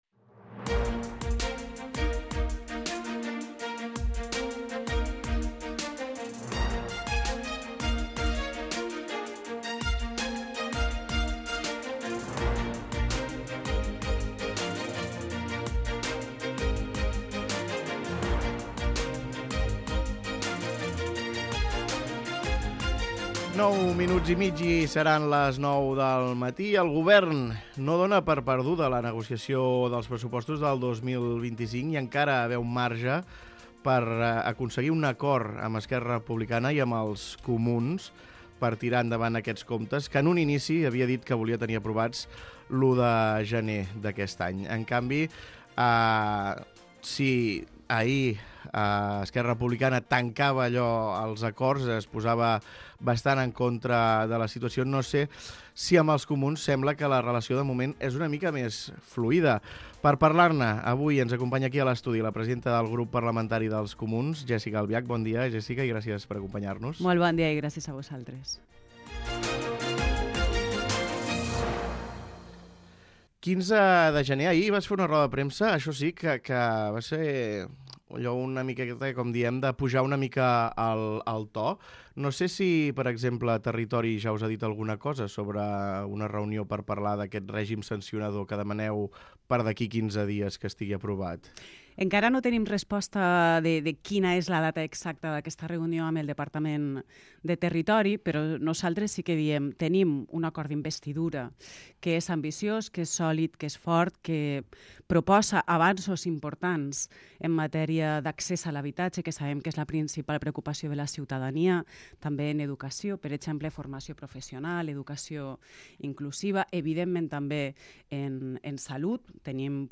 Escolta l'entrevista a Jéssica Albiach, presidenta dels Comuns al Parlament de Catalunya